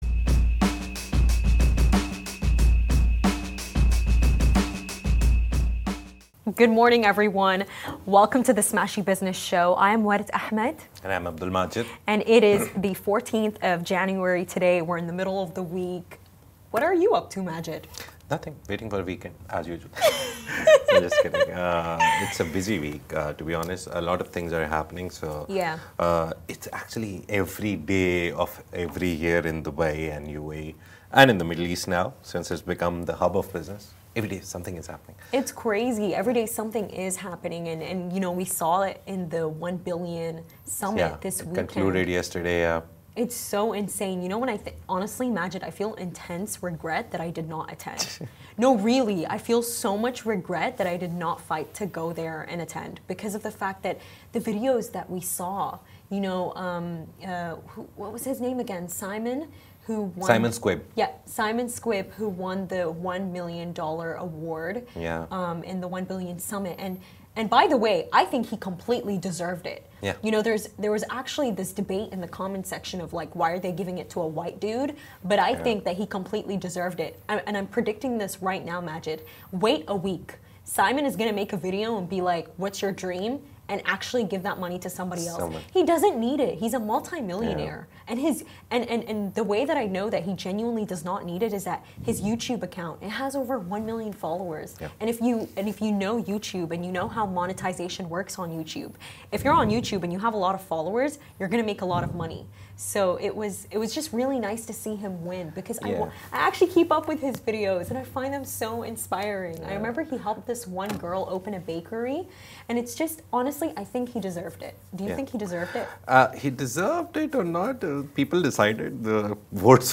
The Smashi Business Show is where Smashi interviews the business leaders who make a difference in this great city.